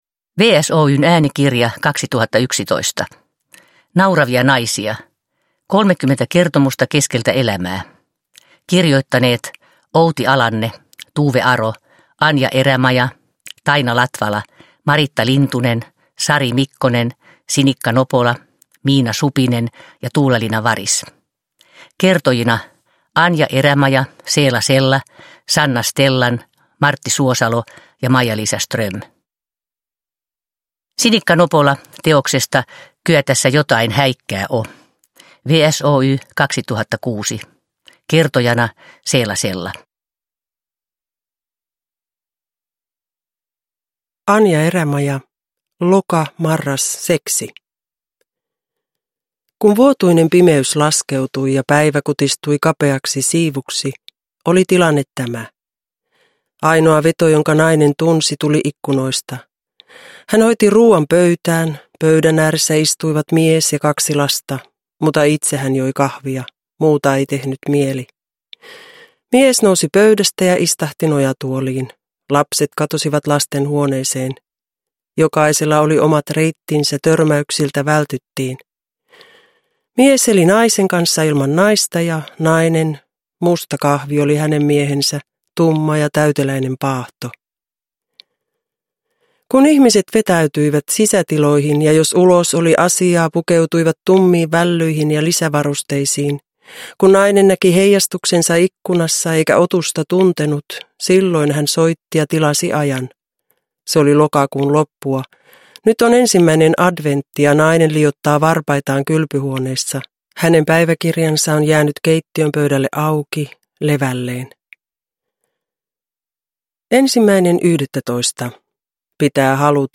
Rakkaudelle ja kuolemalle hymyillään ja nauretaan niin savon- kuin hämeenmurteella, niin hautausarkkujen äärellä kuin kuumalla konepellillä.
Viihdyttävä äänikirja vie kuulijan elämän katkeransuloiseen ytimeen. Ansiokkaat näyttelijät puhaltavat tarinat eloon kaikissa värisävyissä.